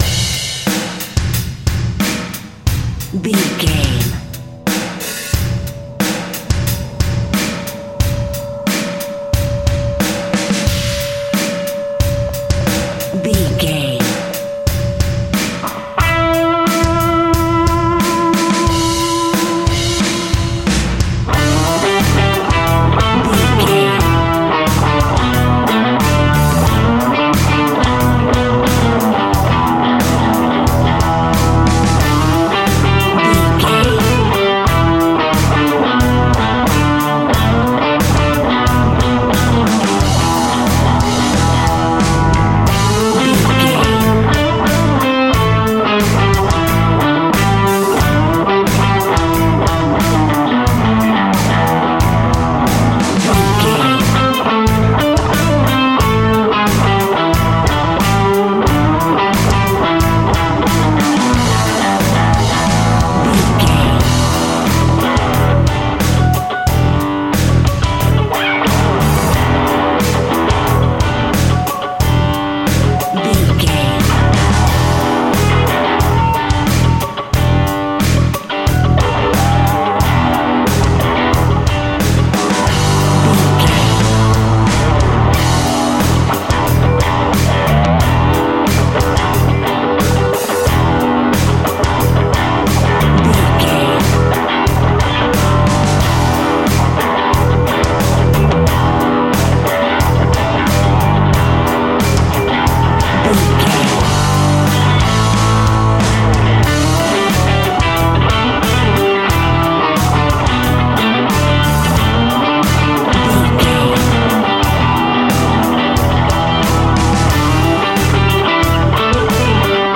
Ionian/Major
energetic
driving
heavy
aggressive
electric guitar
bass guitar
drums
heavy metal
heavy rock
distortion
hard rock
Instrumental rock
hammond organ